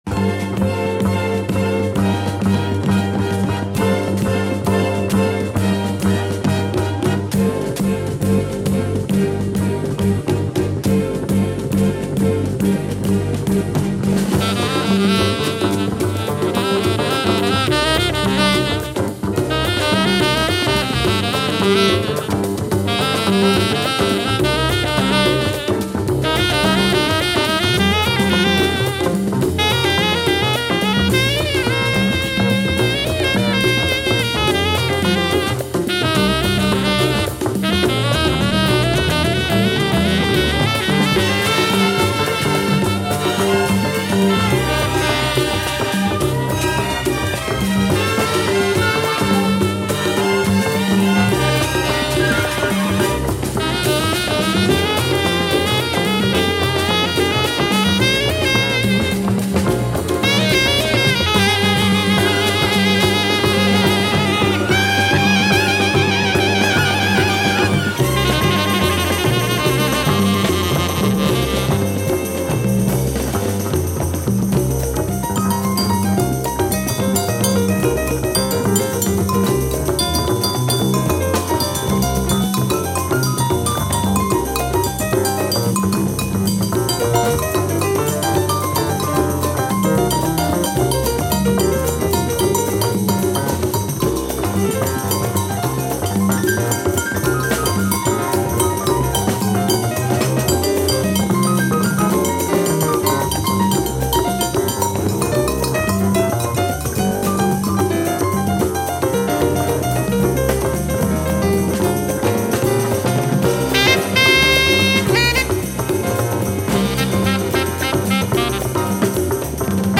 Jazz waltz